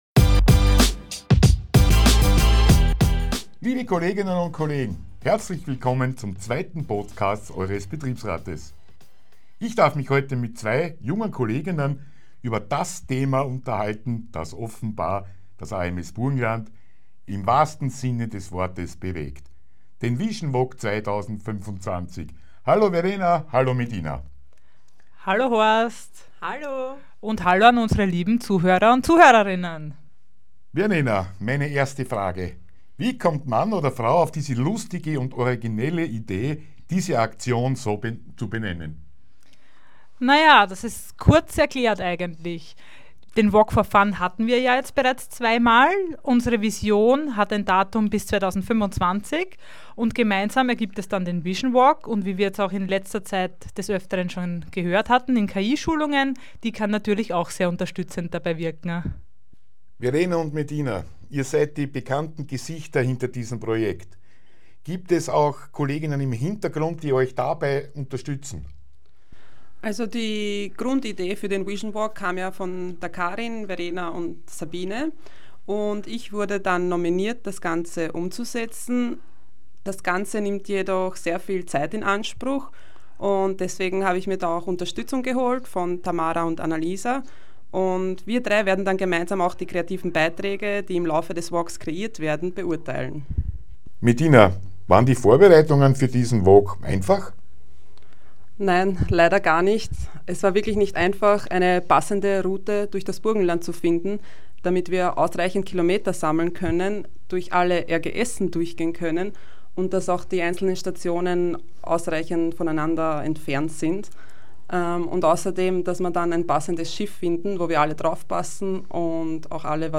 sie haben unsere Fragen zum "Vision Walk 2025" frisch und frech und mit ganz viel Charme beantwortet. Ein kleines Gewinnspiel gibt es dieses mal auch!